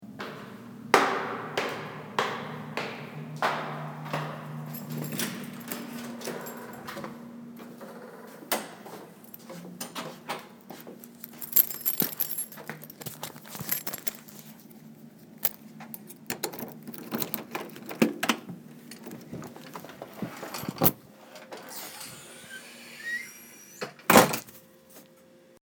Field Recording 9
Sounds Heard: Footsteps in stairwell, keys jingling, key sliding into lock, door unlocking, door closing
Keys.mp3